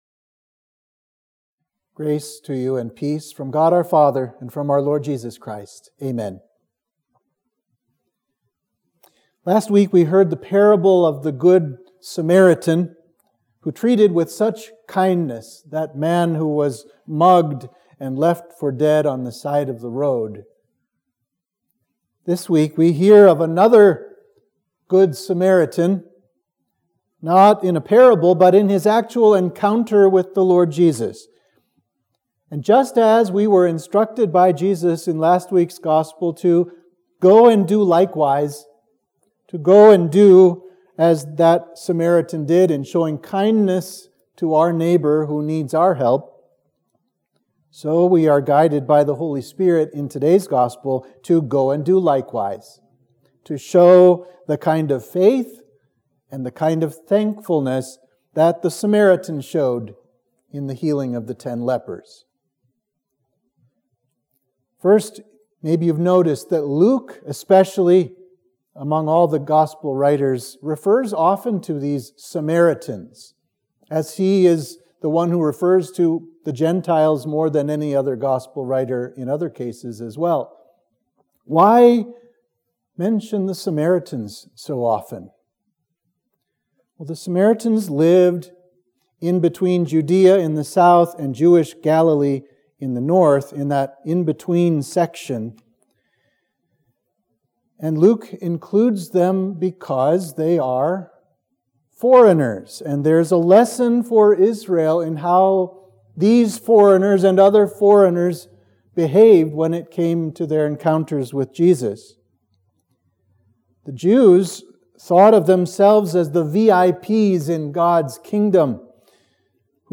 Sermon for Trinity 14